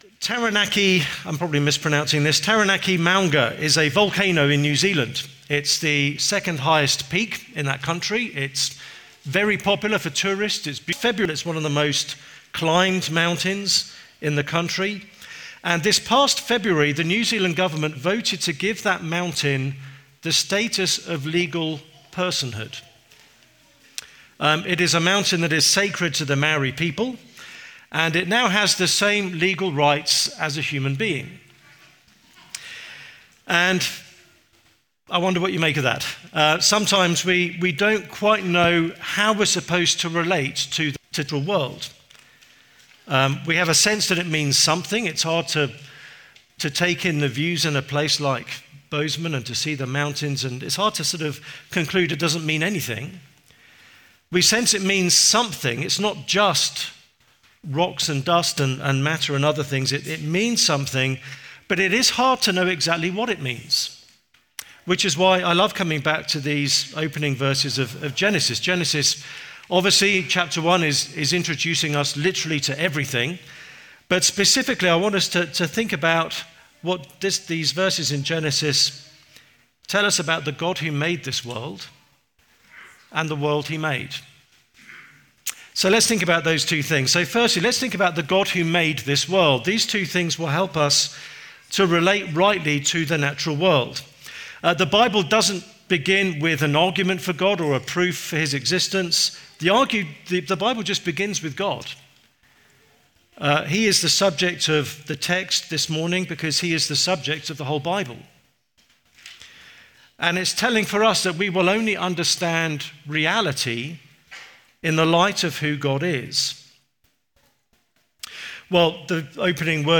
Weekly teaching audio brought to you by Redeemer Church in Bozeman, MT